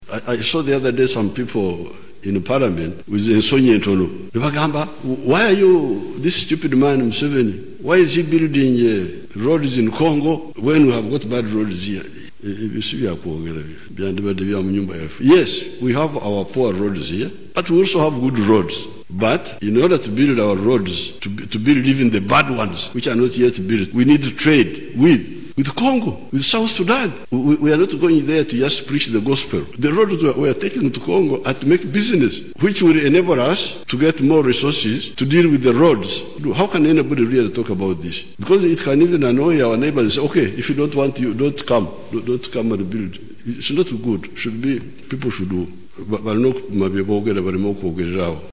Bw’abadde ayogerako eri eggwanga, nga Uganda ejjaguza okuweza emyaka 58 bukya efuna bwetwaze mu 1962, Museveni agambye nti Uganda okutumbula eby’obusuubuzi, erina okuzimba enguudo eziyingira mu ggwanga erya Congo ne South Sudan, kiyambeko abasuubuzi okutambula obulungi.
Ku ntikko y’omukolo ogubadde mu State House Entebe mu nkola eya ‘Science’, Museveni akangudde ku ddoboozi nti enguudo zirina okuzimbibwa, kuba zigenda kuyamba nnyo okutumbula onkolagana mu by’obusuubuzi n’okulongoosa eby’entambula.